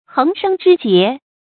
注音：ㄏㄥˊ ㄕㄥ ㄓㄧ ㄐㄧㄝ ˊ
橫生枝節的讀法